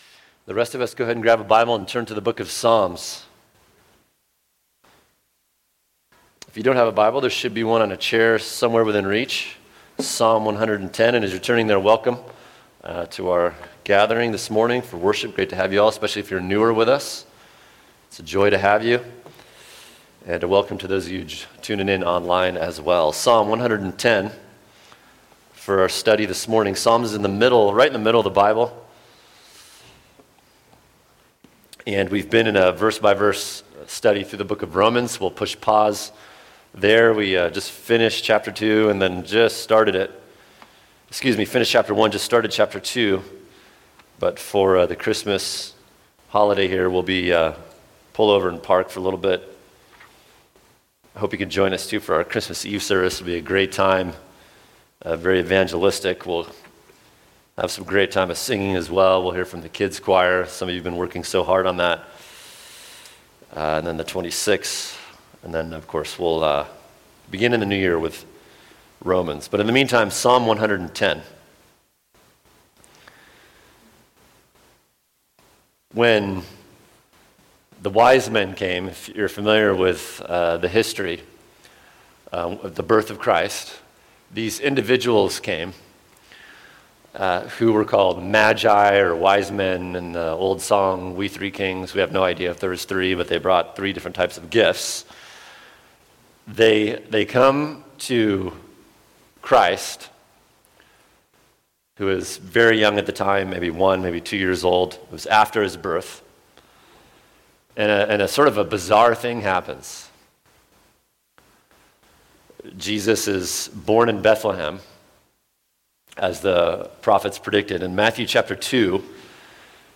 [sermon] Psalm 110 Christmas and the King | Cornerstone Church - Jackson Hole